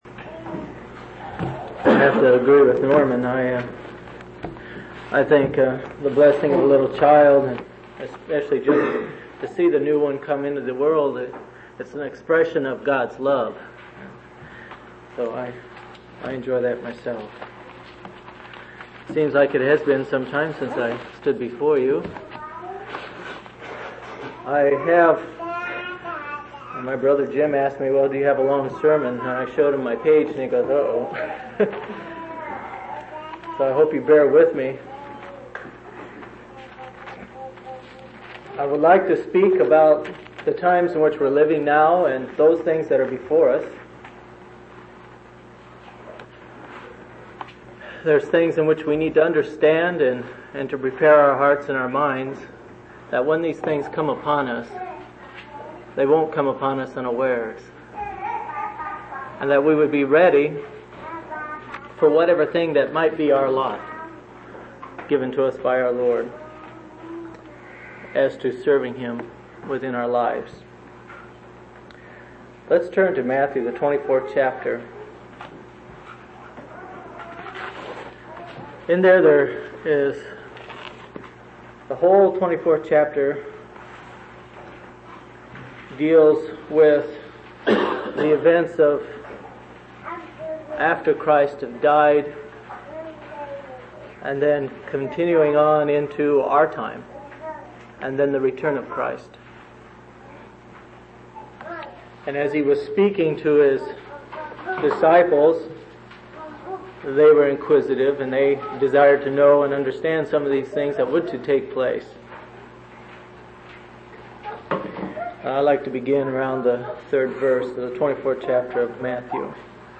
5/22/1983 Location: Phoenix Local Event